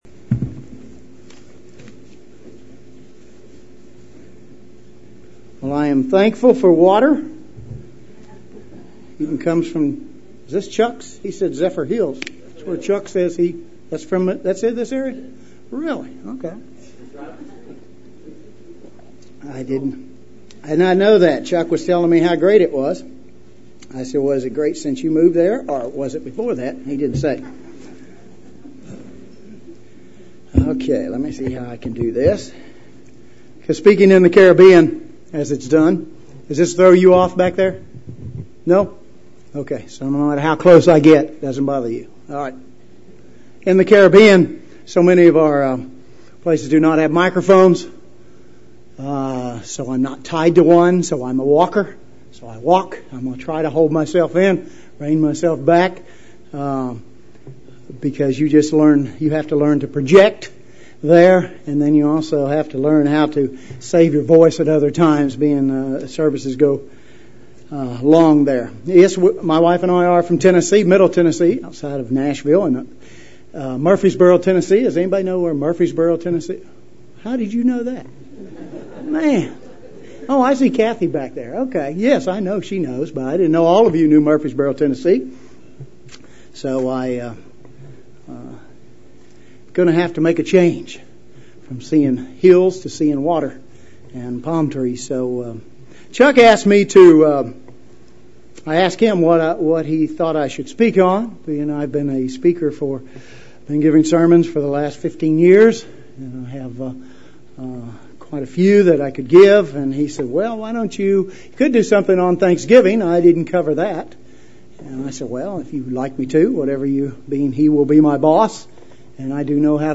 Spiritual Exercise | United Church of God